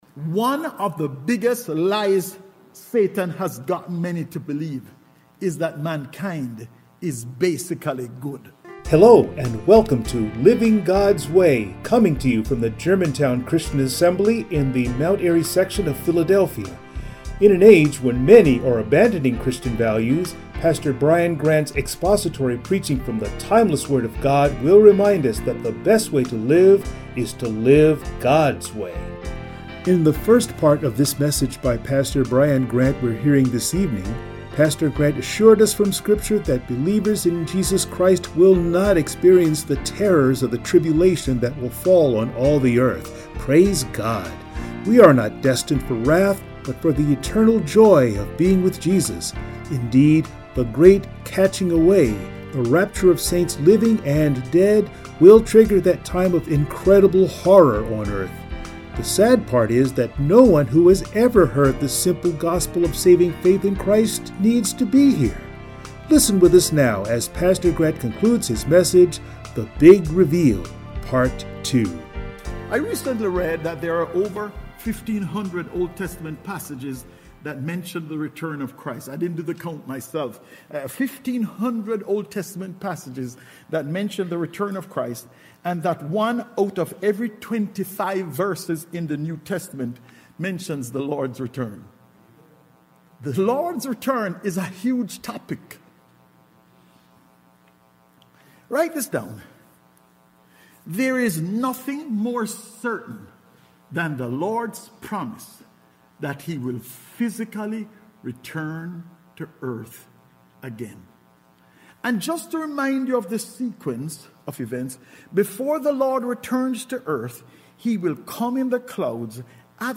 Passage: Zechariah 14:1-11 Service Type: Sunday Morning